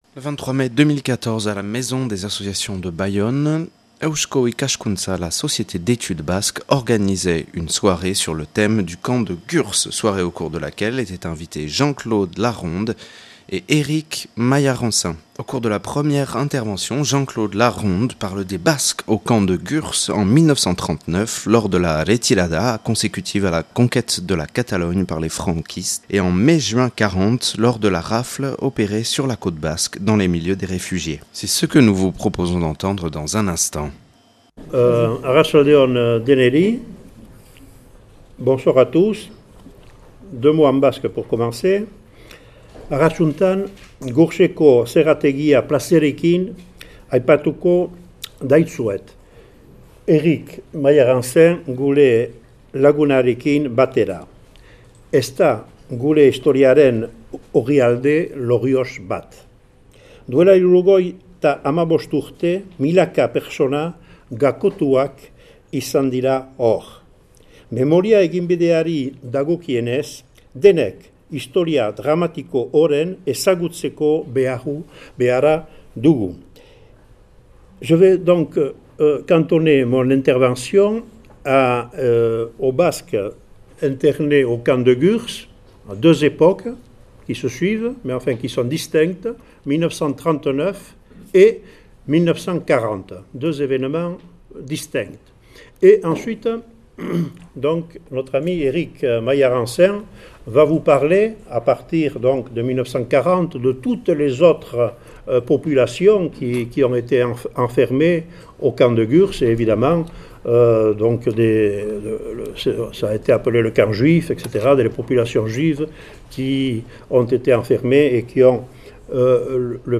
(Enregistré le 23/05/2015 à la Maison des associations de Bayonne).